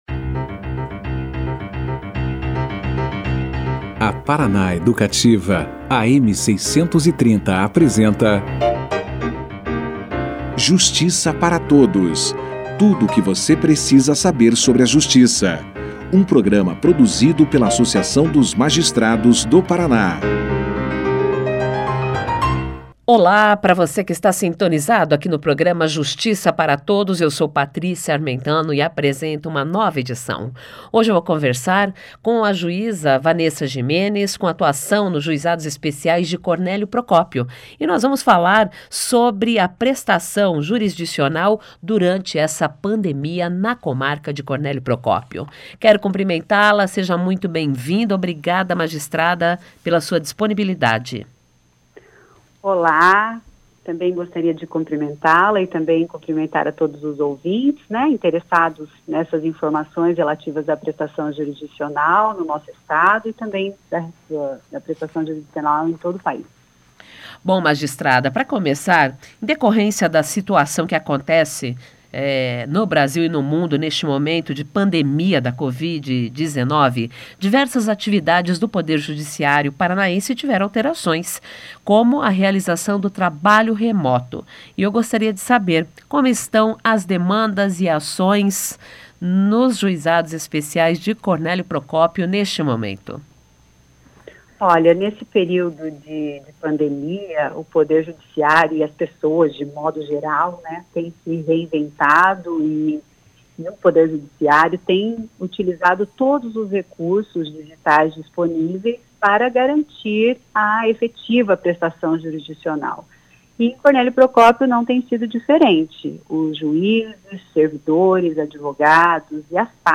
>>Clique Aqui e Confira a Entrevista na Integra<<
O Programa Justiça Para Todos ouviu a Juíza Vanessa Gimenez da Comarca de Cornélio Procópio. A magistrada que atua nos Juizados Especiais daquele município falou como está acontecendo a prestação jurisdicional durante a crise da COVID-19. Segundo ela, somente pelos Fóruns de conciliação virtual foram realizadas neste período, 1.054 audiências para a resolução de conflitos.